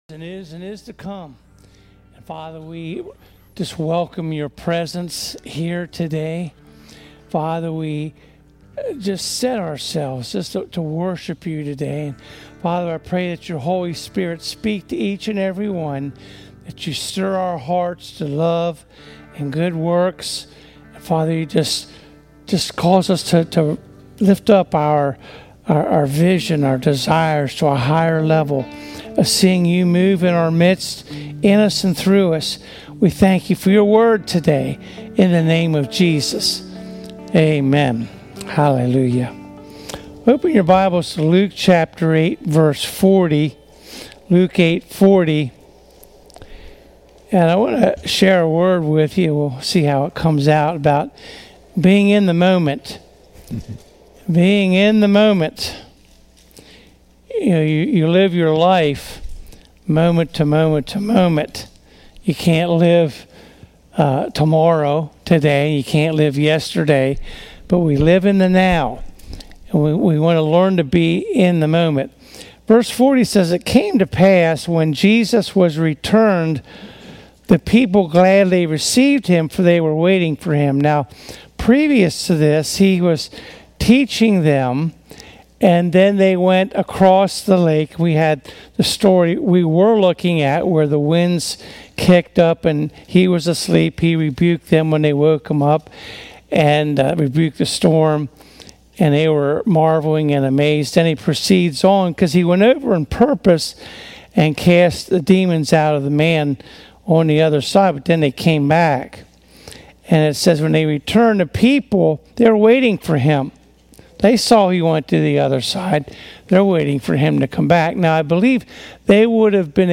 Preaching Service